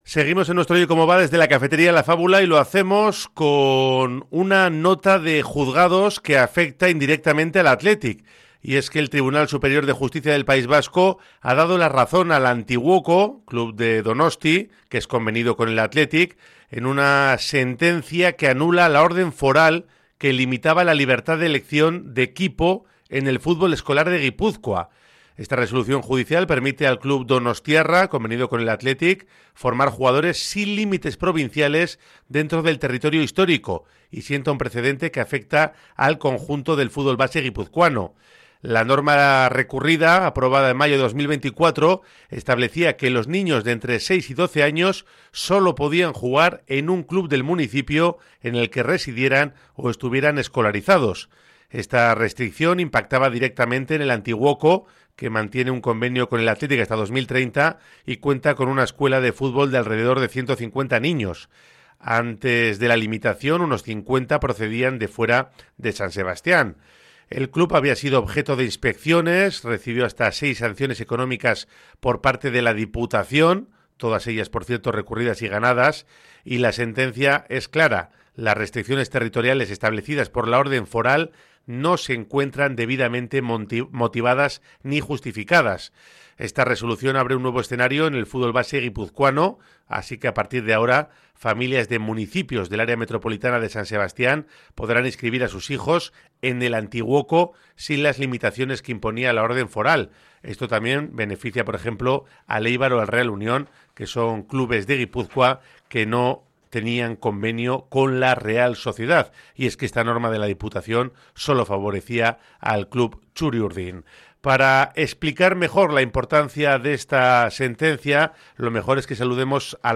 Según se explica en antena, la resolución judicial permite al Antiguoko, club convenido con el Athletic, formar jugadores sin límites provinciales dentro del territorio histórico y sienta un precedente que afecta al conjunto del fútbol base guipuzcoano.